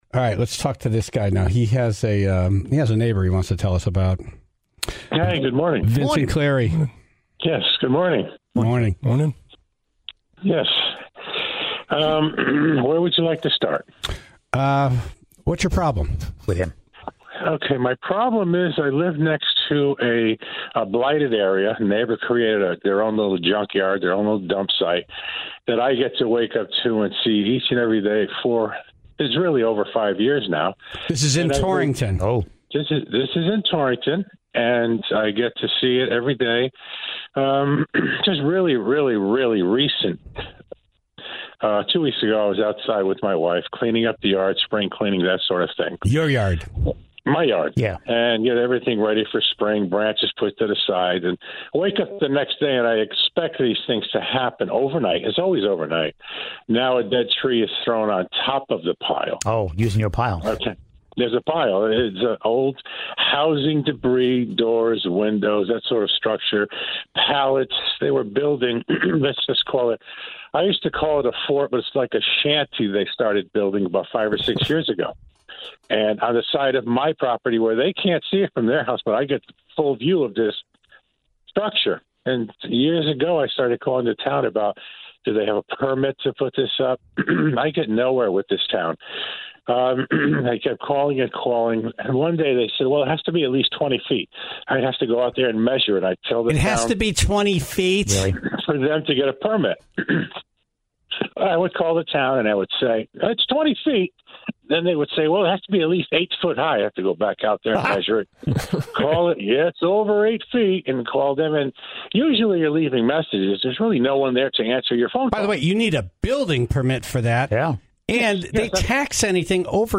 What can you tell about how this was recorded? and calls from the Tribe. (26:11) Can elephants predict earthquakes?